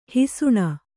♪ hisuṇa